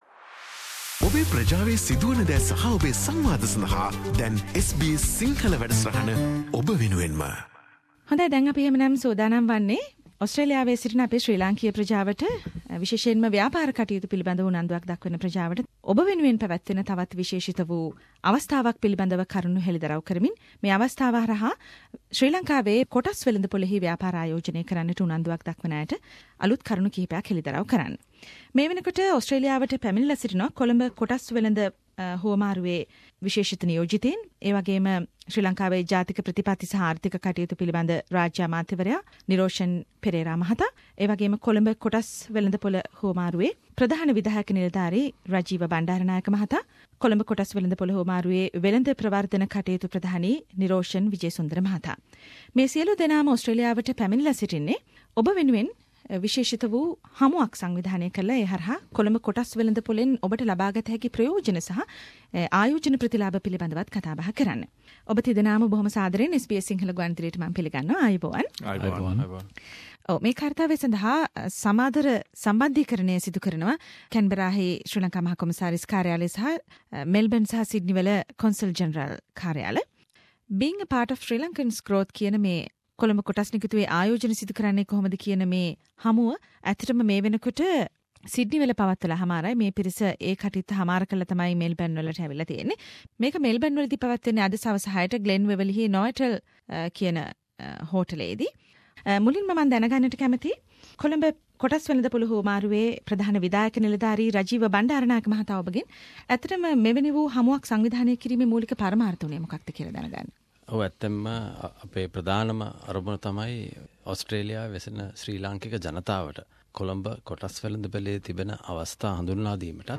How Sri Lankan expatriates can invest in the Colombo stock market – Delegates from Seri Lanka spoke to the SBS Sinhalese